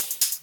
Hihats_Loop B.wav